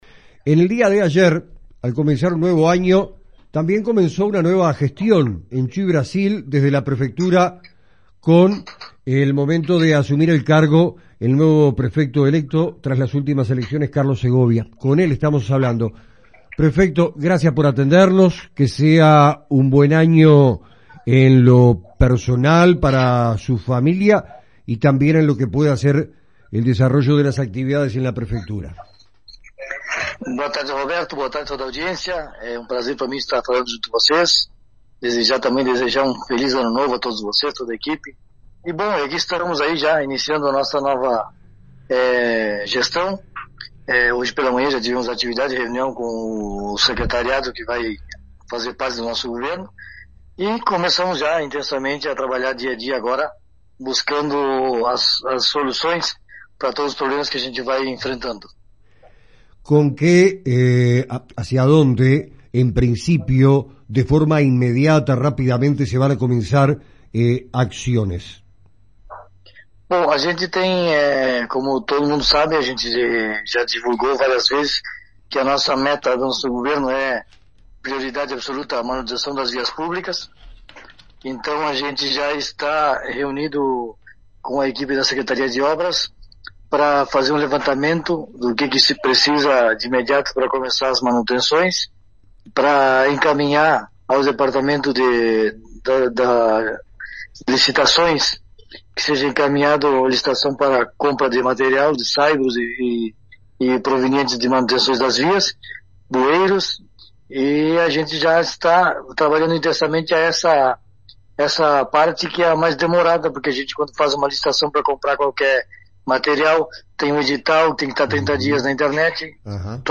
Entrevista a Carlos Segovia: Asume el cargo de Prefeito de la ciudad de Chuí